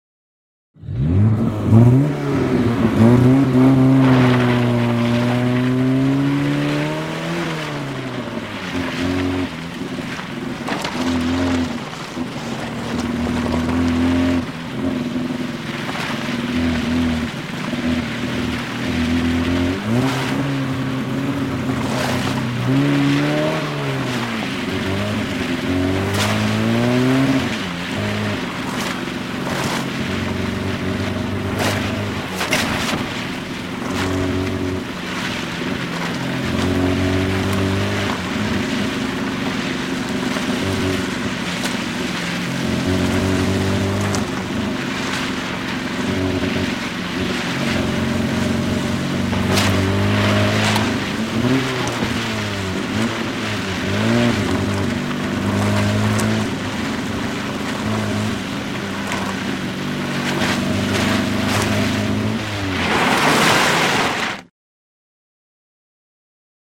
Звук Лада 21015 гоняет по кругу